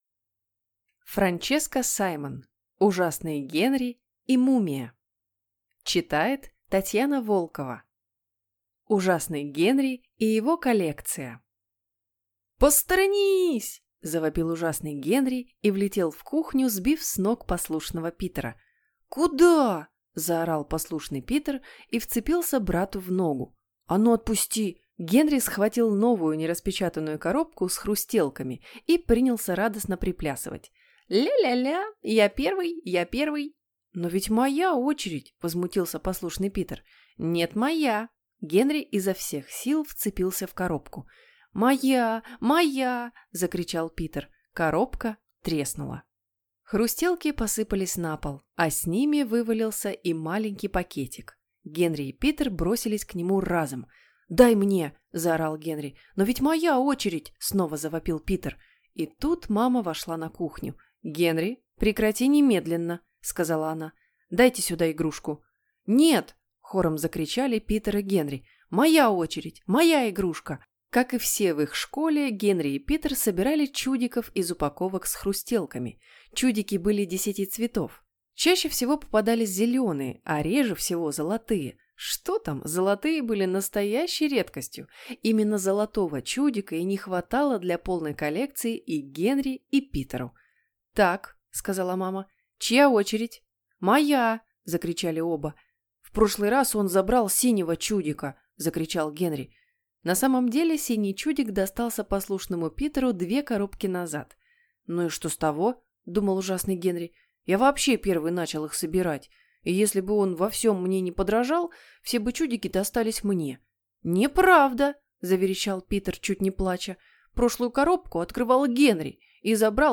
Аудиокнига Ужасный Генри и мумия | Библиотека аудиокниг
Прослушать и бесплатно скачать фрагмент аудиокниги